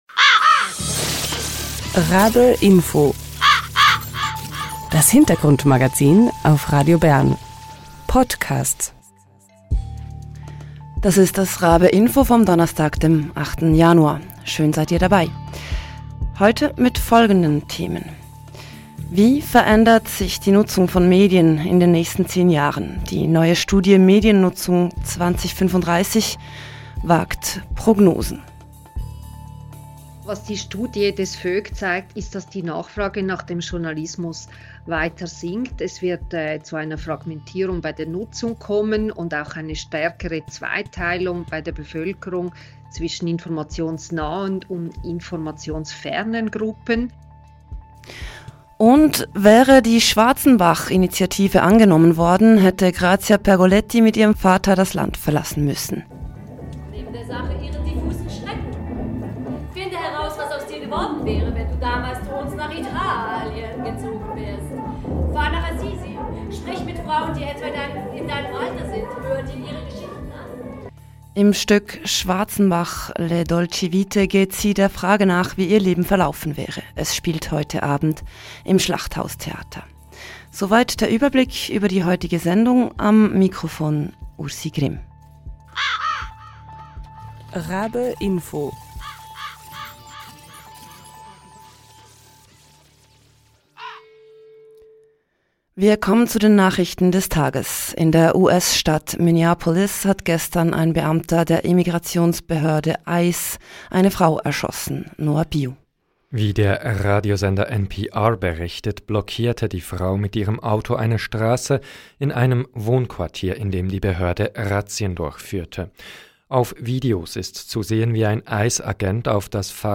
Im Interview hören wir